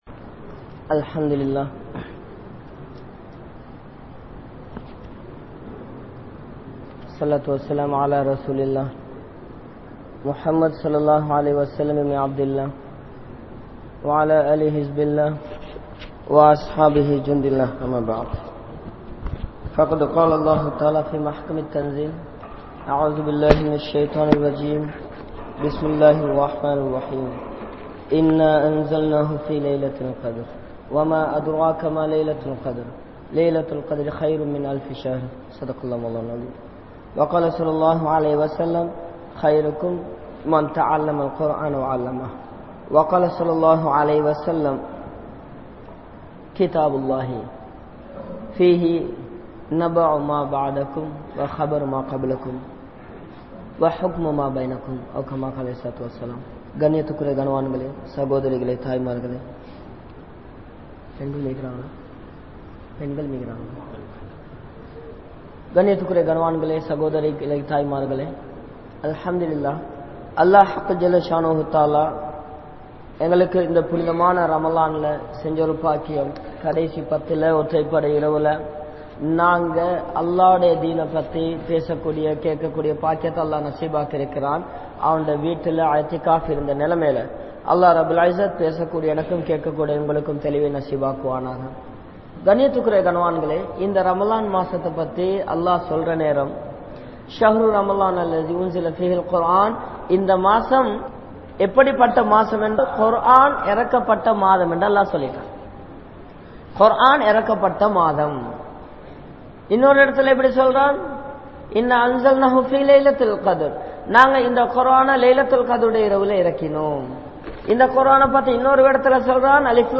Al - Quranum Indraya Samoohamum | Audio Bayans | All Ceylon Muslim Youth Community | Addalaichenai
Grand Jumua Masjith